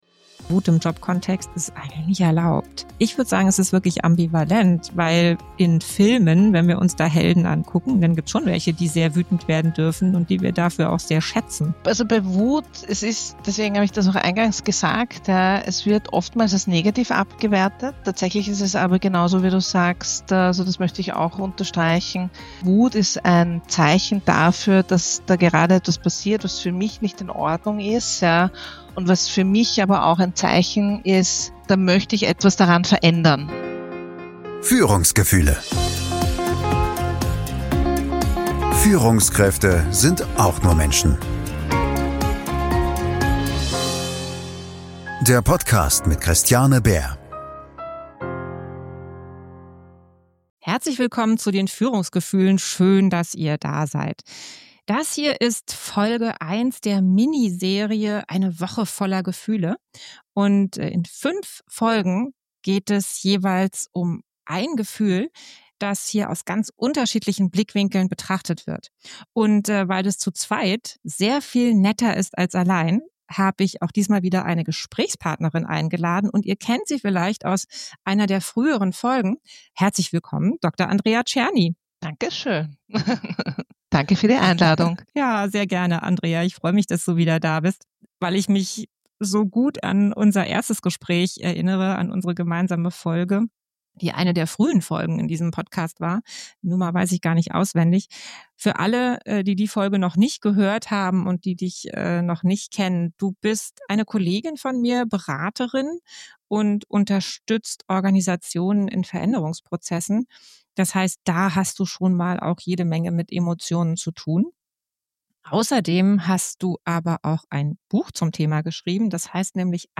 Ein Gespräch, das vieles nochmal klarer macht: Wut ist unbequem.